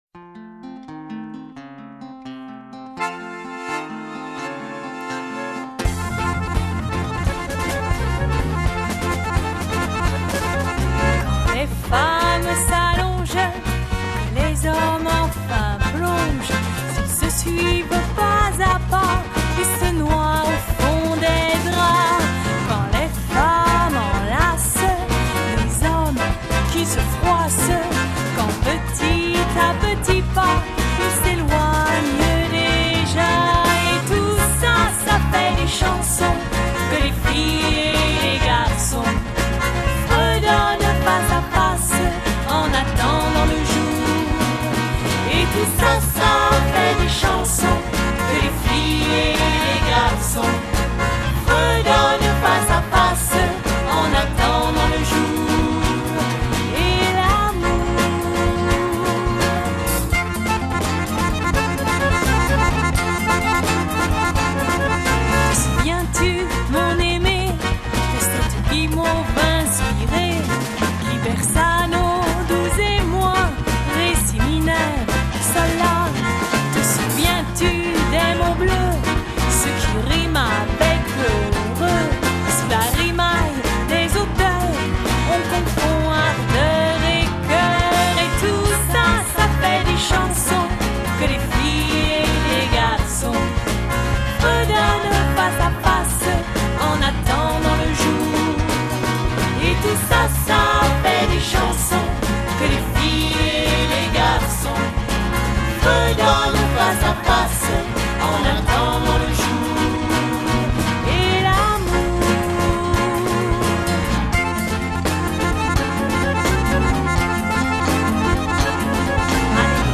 chant, accordéon, guitares, basse, batterie